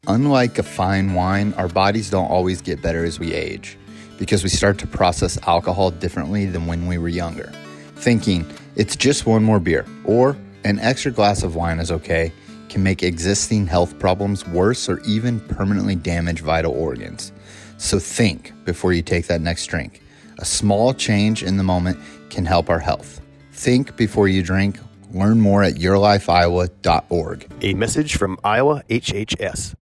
Think-Before-you-Drink-Radio-30second-Male-Over40.mp3